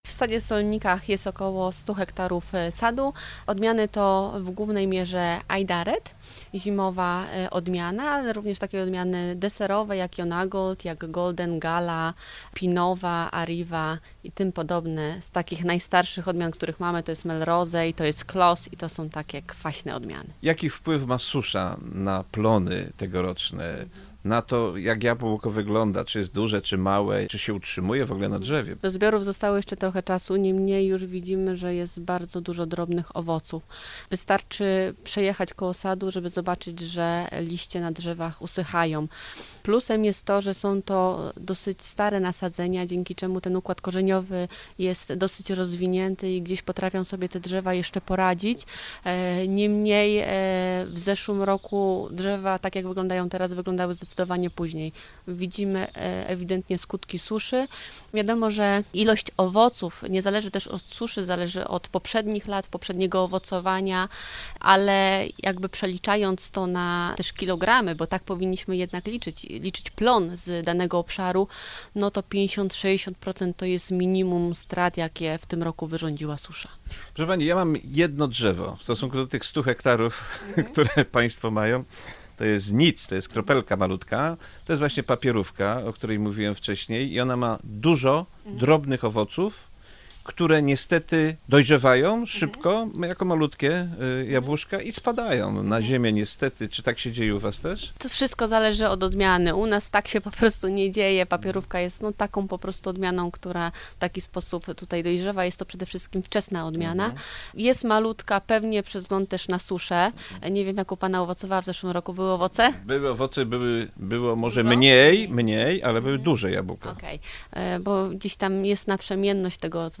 Znów nie unikniemy tematu suszy, która w tym roku odczuwalna jest mocno, także w uprawach drzew owocowych. Odwiedzimy sad w Solnikach pod Kożuchowem.
Rozmowa
ogrodowy-4-08-sad-solniki.mp3